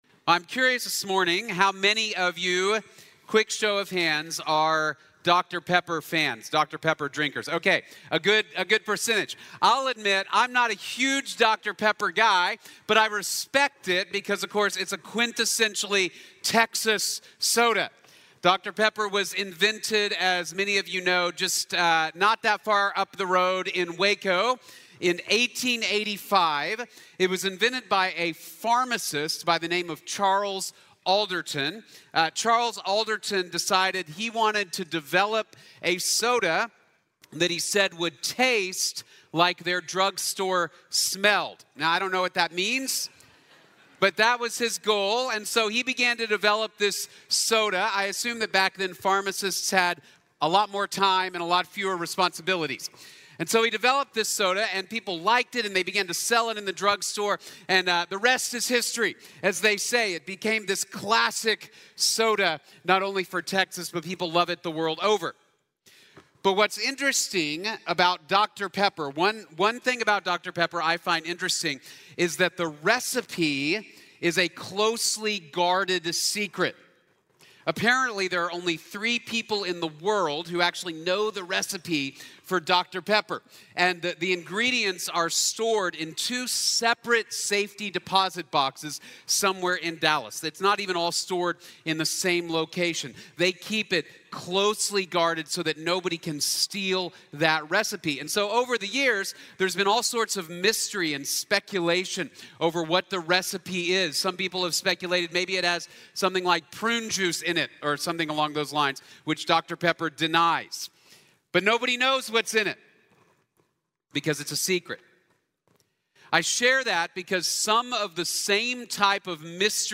Cuerpo y Espíritu | Sermón | Iglesia Bíblica de la Gracia